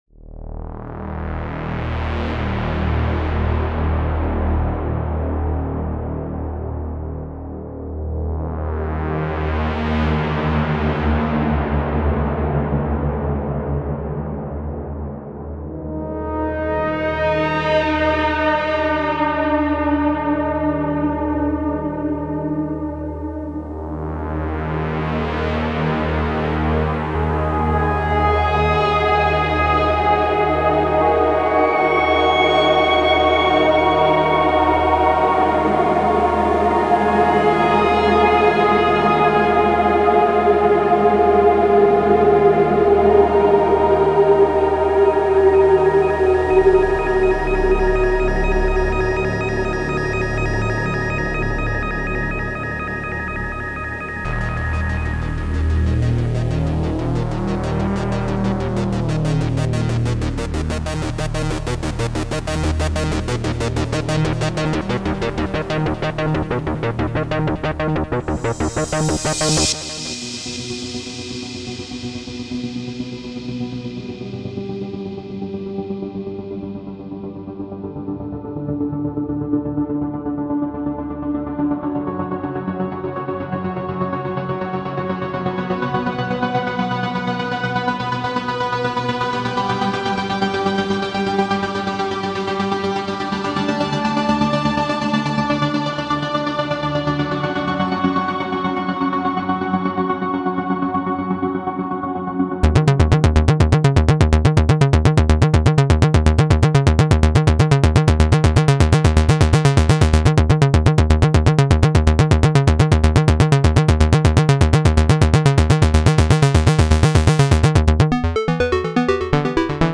synth presets for u-he repro-1 synthesizer
Prometheus for Repro-1 features a wide range of presets exploring both vintage and modern sound.
Repro-1 has superb filters and beefy oscillators, making it ideal for baselines, arpeggios and musical monophonic sequences, but you’ll also find some cinematic sounds and thanks to some programming trickery even some polyphonic pads.
Any of the sounds of course can have their sequencer switched off and used normal patches, basses, leads, atmospheres and mono-phonic pad sounds, giving you a full 120 sounds to work with, all tagged for use in U-he’s new browser system.
The effects section features heavily here, reverb and chorus accentuating atmospheric patches even further, while subtle distortion and EQ is used judiciously on patches that benefit from a dry and powerful sound, such as basses.